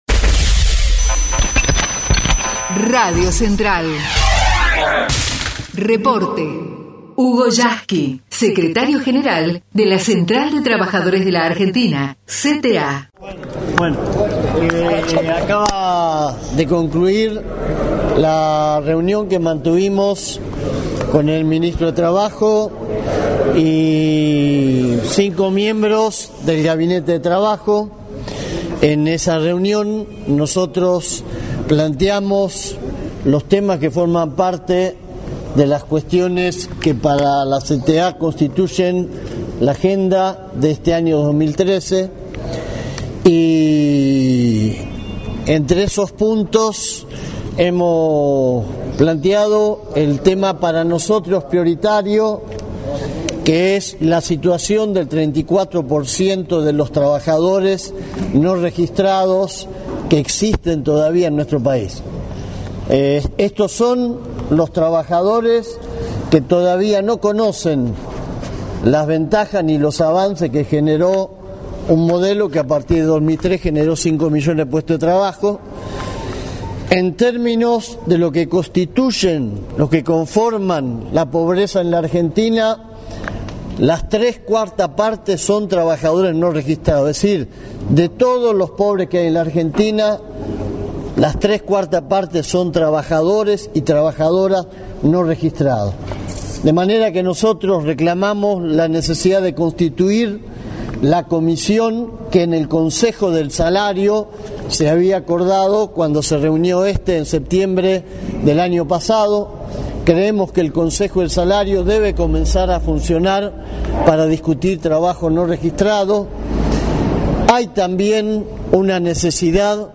reunion_yasky_tomada.mp3